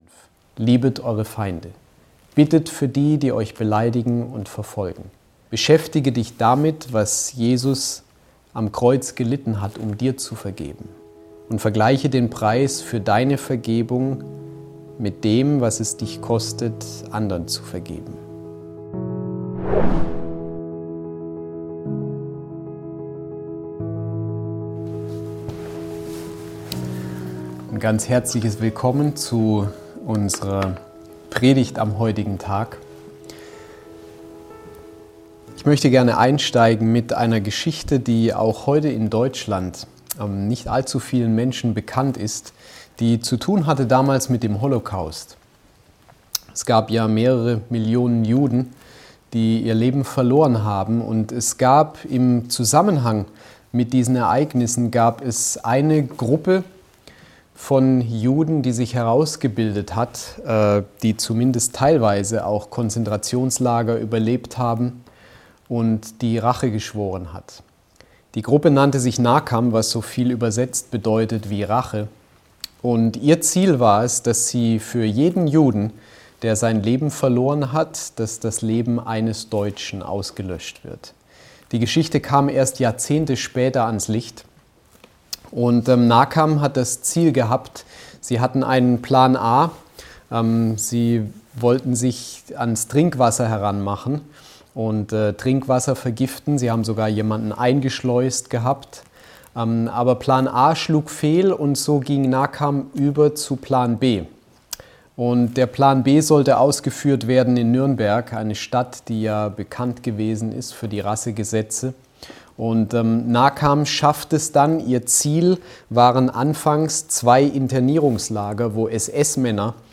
DasWort - Predigten Podcast